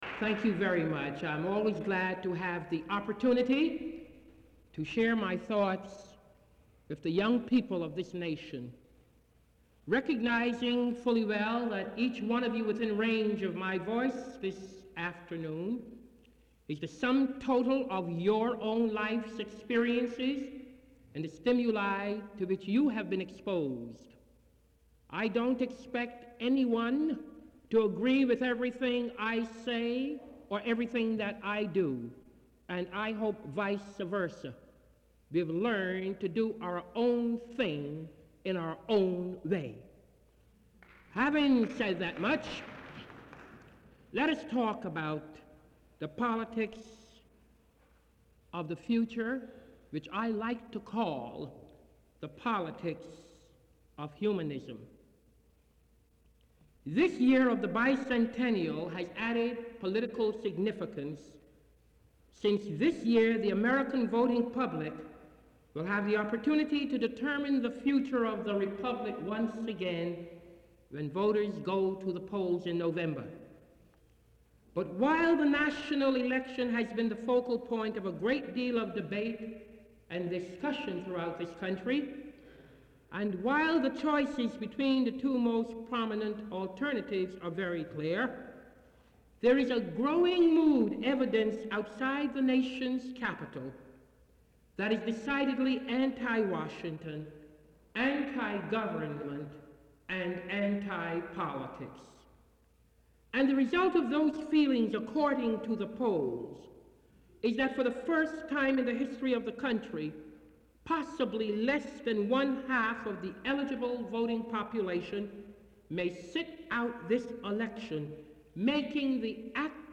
Lecture Title
Thomas C. Hennings, Jr. Memorial Lecture